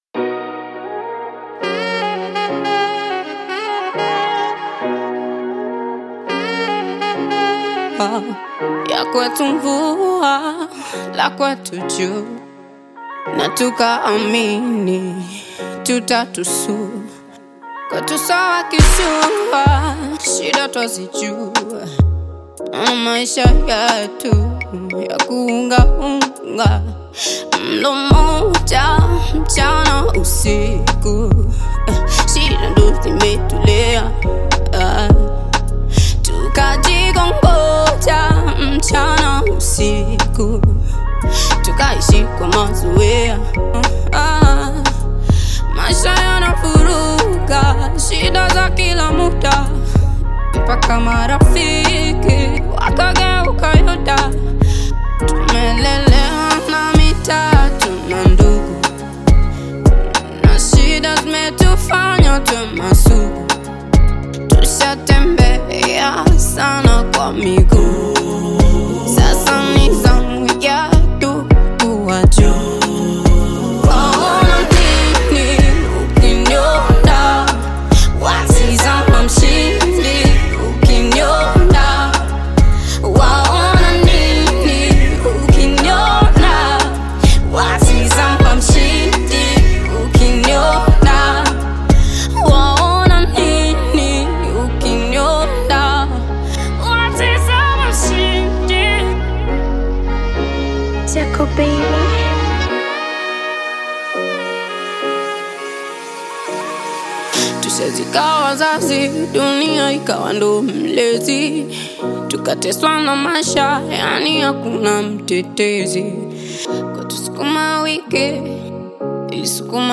Tanzanian Bongo Flava
Bongo Flava